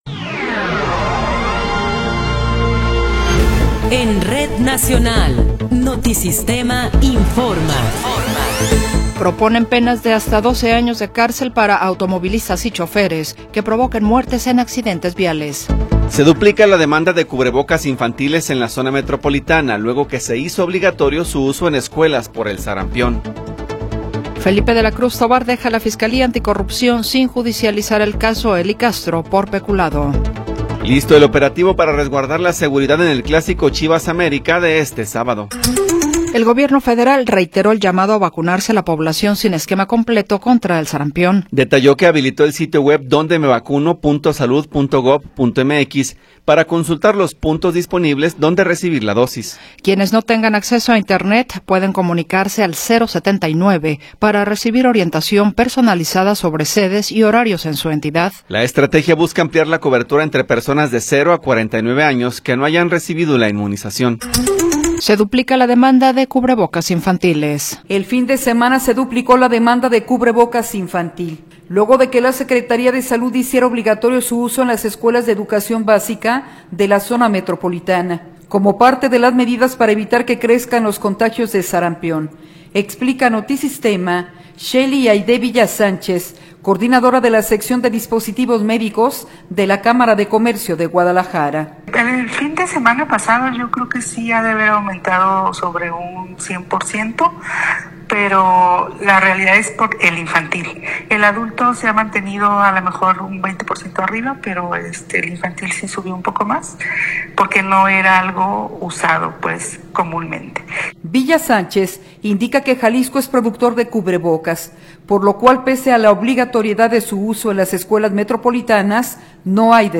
Noticiero 14 hrs. – 12 de Febrero de 2026
Resumen informativo Notisistema, la mejor y más completa información cada hora en la hora.